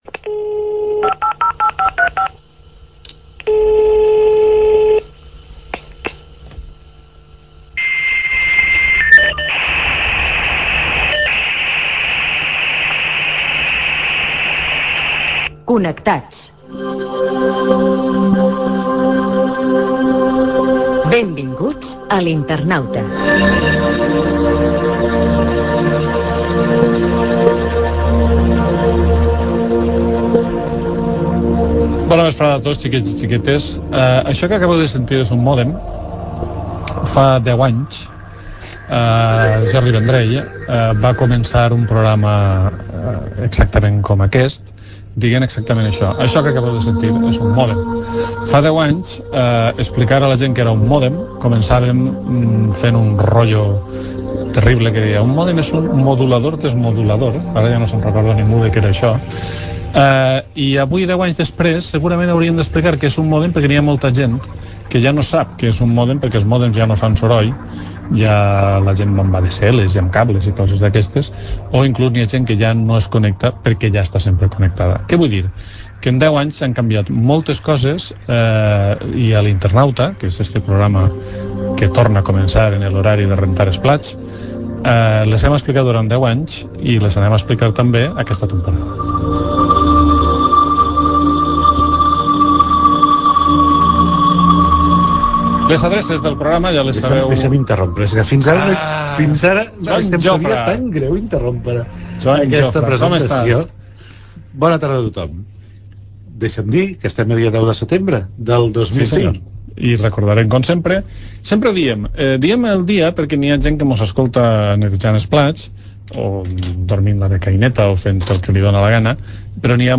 Sintonia, presentació amb l'anunci que el programa ja es pot escoltar en podcast
Divulgació